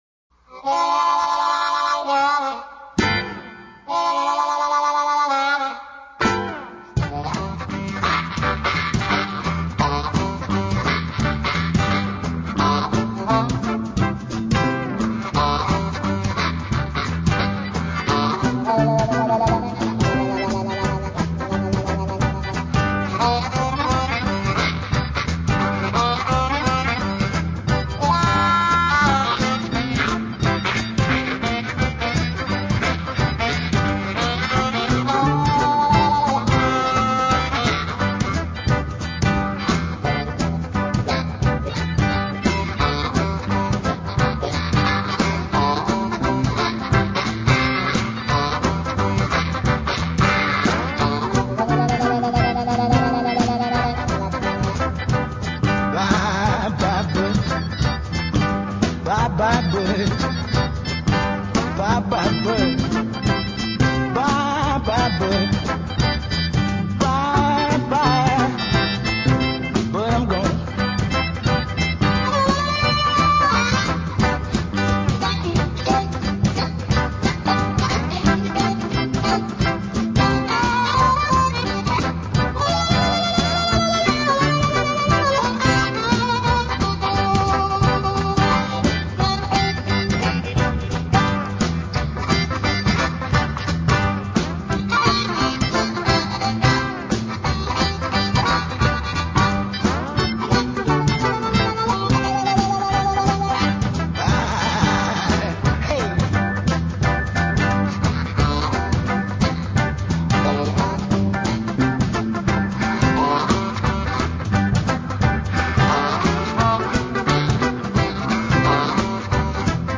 Hohner Marine Band 364/24 C (M36401)